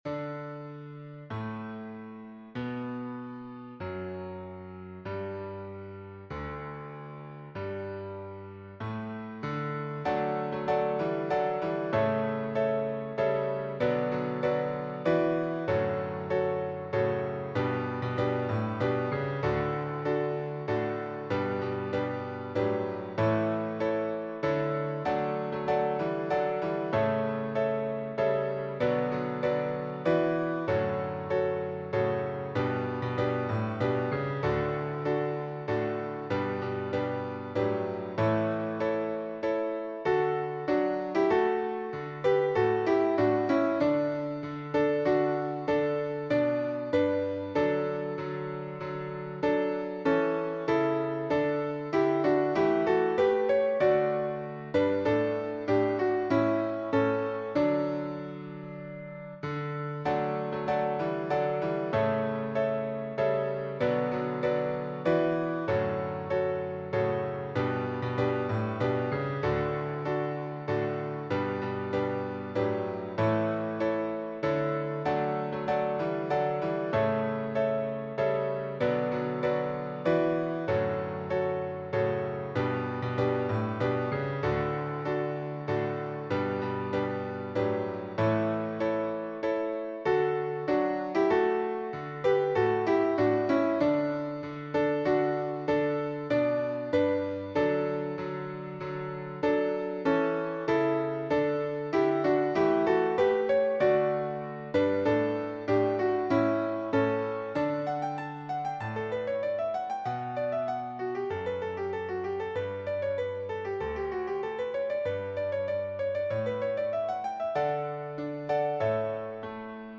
SATB
A simple and fun mash-up of The Iron Rod and Pachelbel's Canon in D.